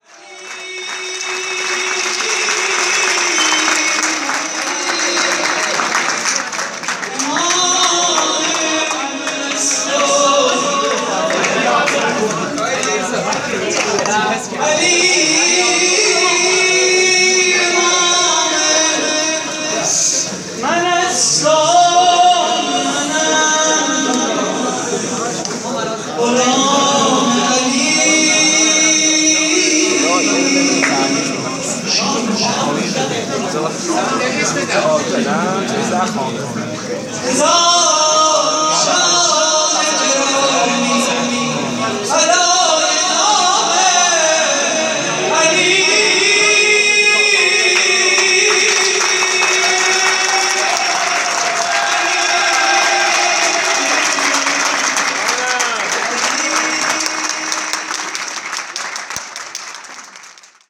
مراسم جشن ولادت امام حسن مجتبی (ع)